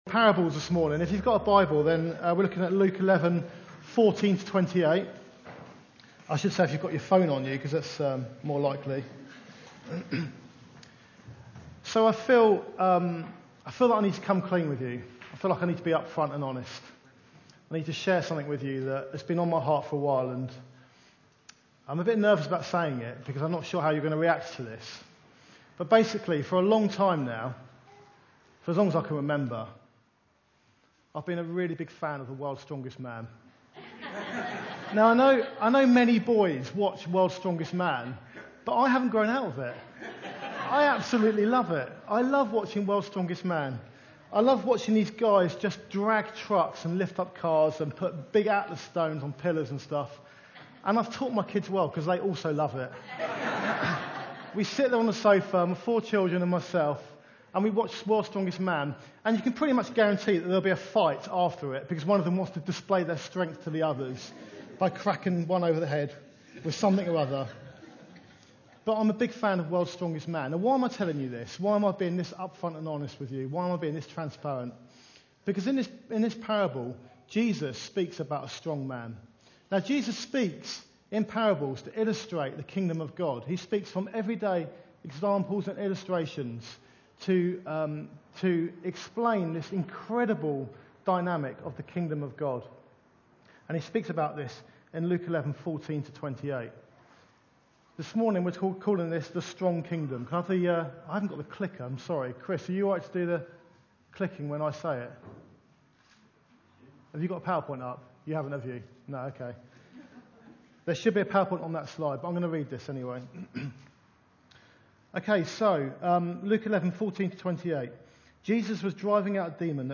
Service Type: Sunday Meeting